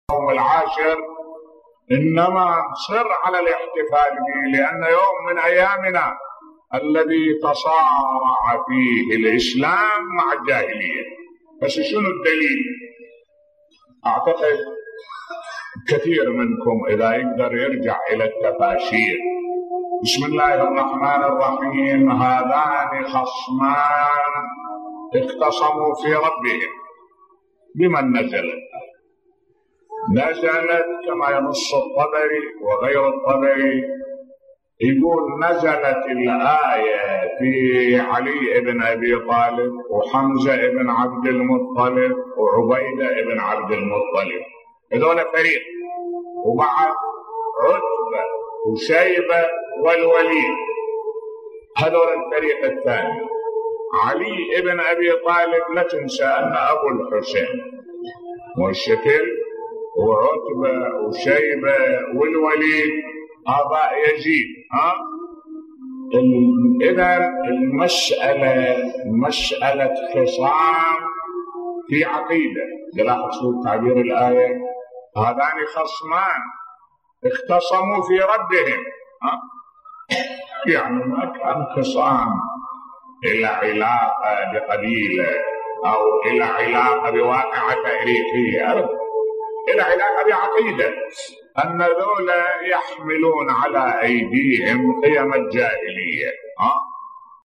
ملف صوتی لماذا الاصرار على احياء يوم العاشر من المحرم بصوت الشيخ الدكتور أحمد الوائلي